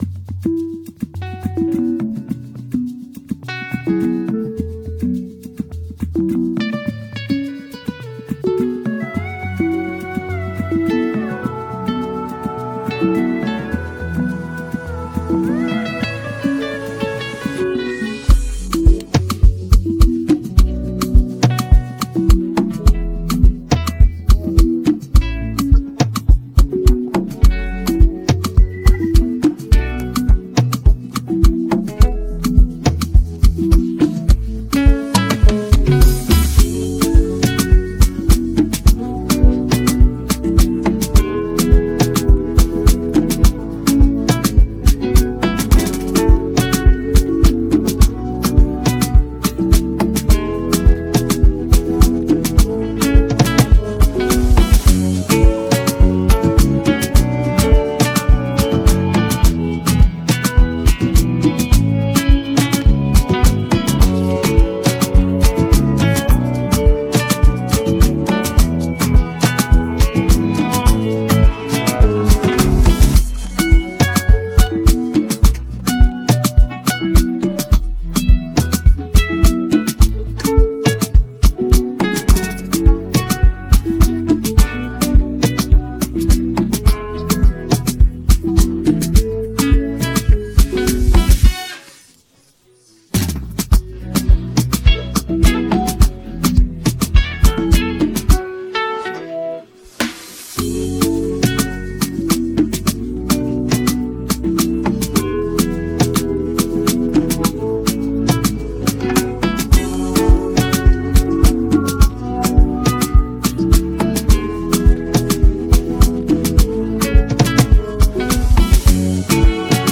amazing amapiano instrumental